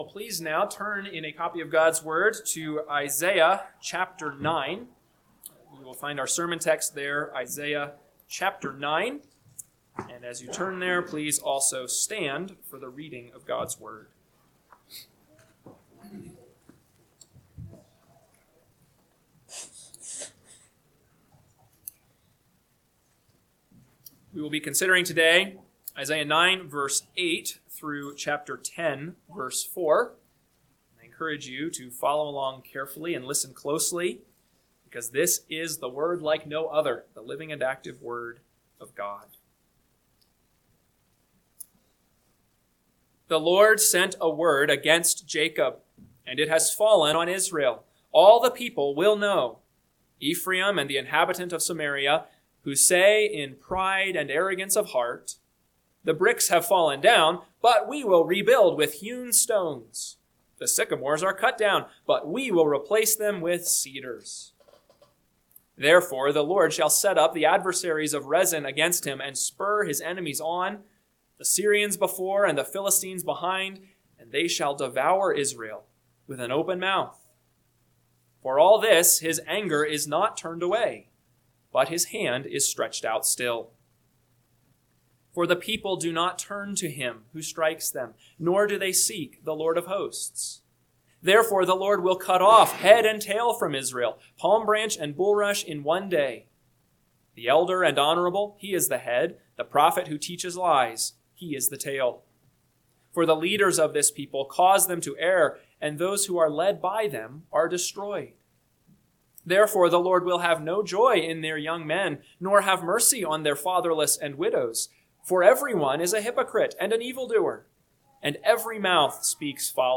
AM Sermon – 1/18/2026 – Isaiah 9:8-10:4 – Northwoods Sermons